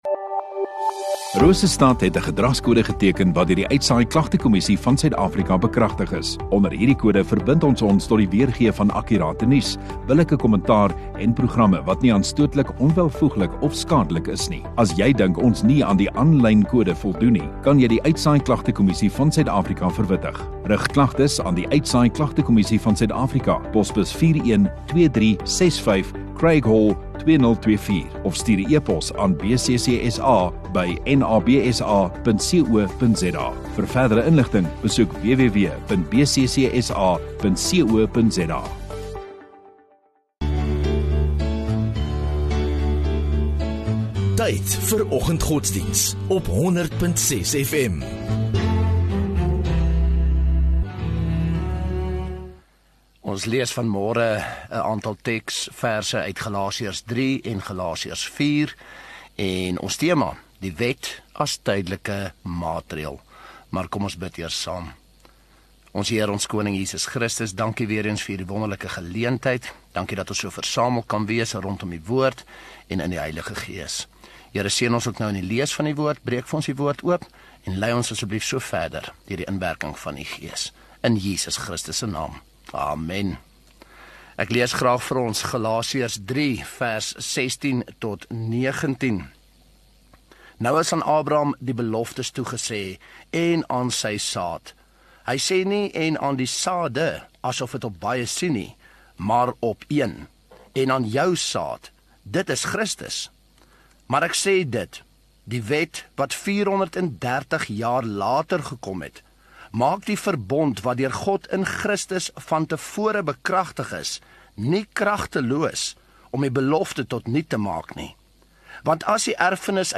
20 Mar Donderdag Oggenddiens